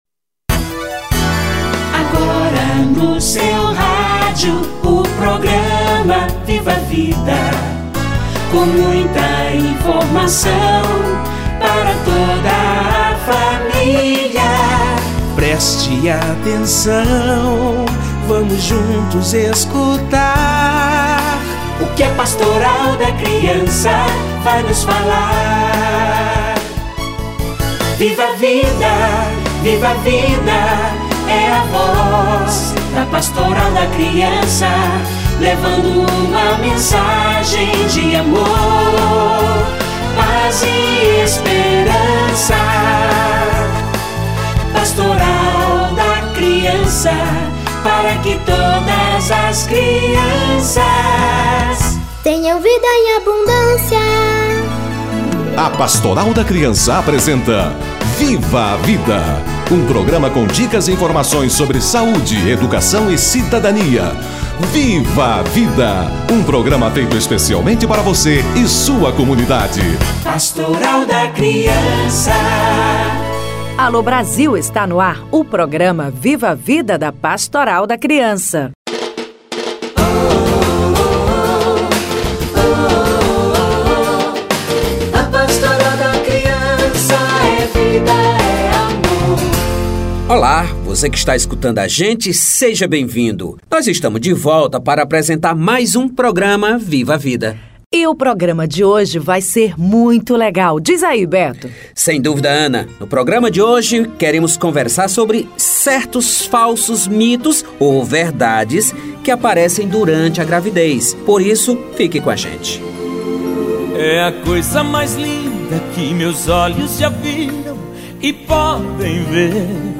Mitos e queixas da gestante - Entrevista